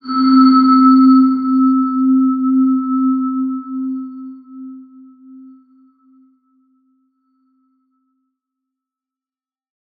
X_BasicBells-C2-mf.wav